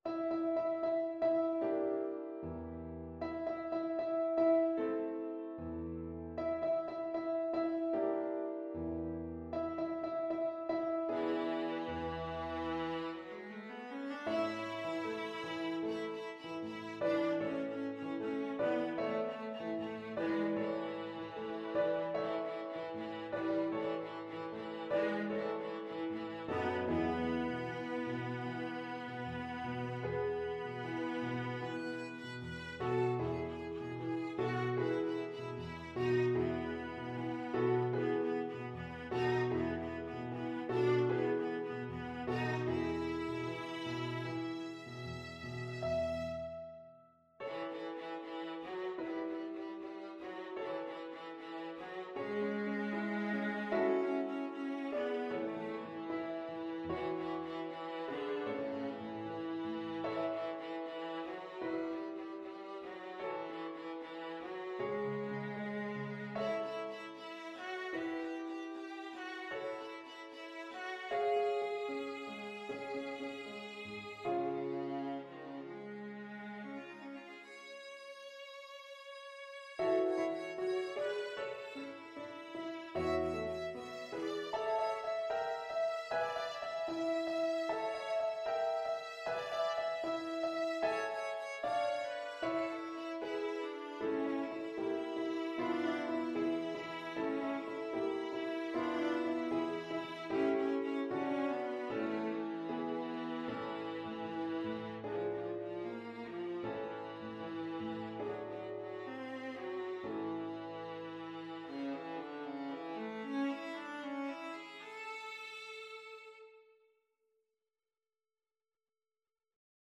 Viola
=76 Allegretto lusinghiero =104
2/4 (View more 2/4 Music)
A major (Sounding Pitch) (View more A major Music for Viola )
Classical (View more Classical Viola Music)